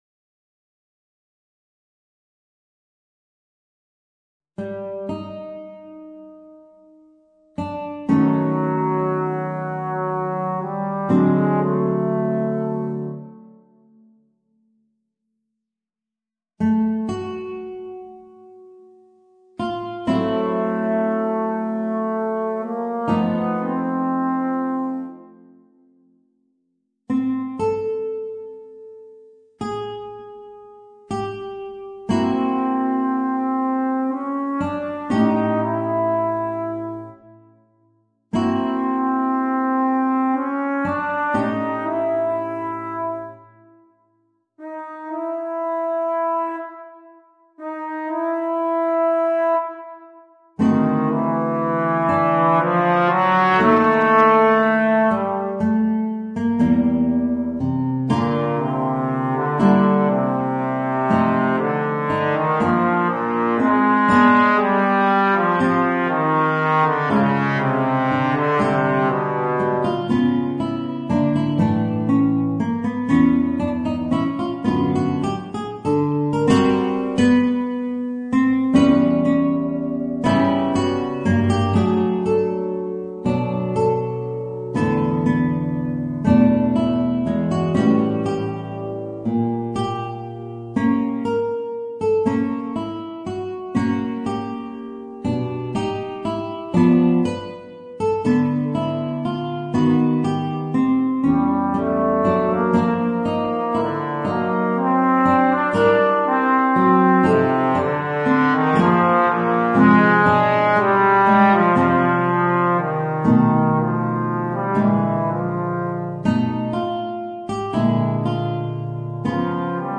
Voicing: Trombone and Guitar